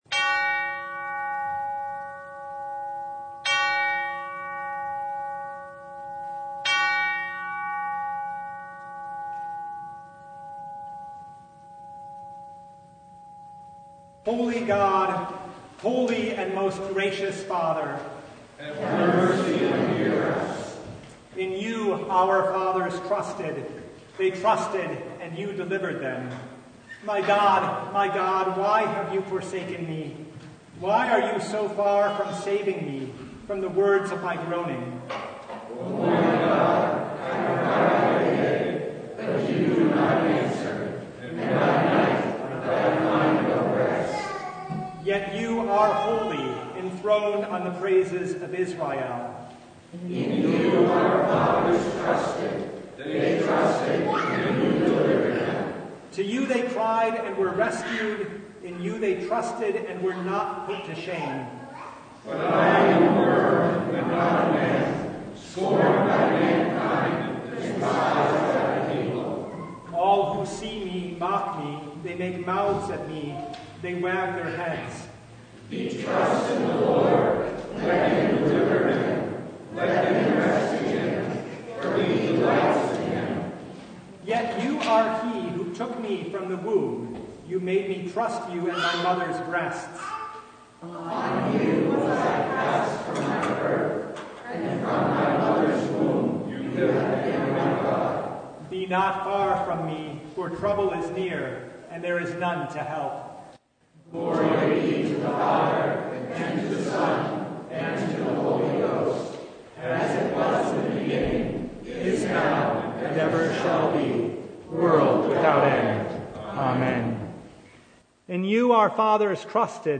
Service Type: Lent Midweek Noon